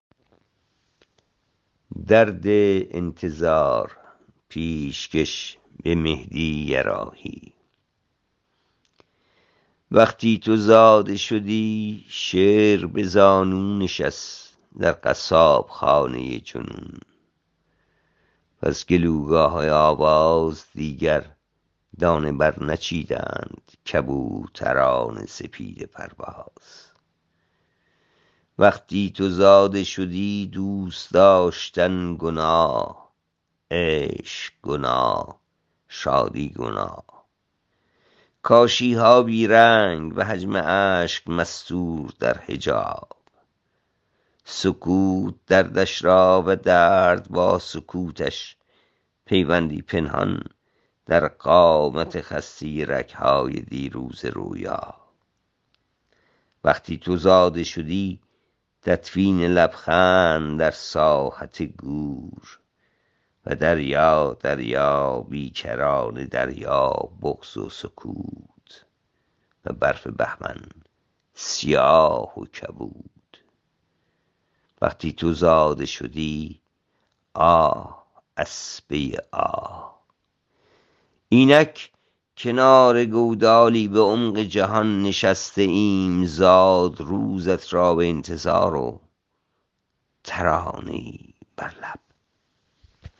این شعر را با صدای شاعر بشنوید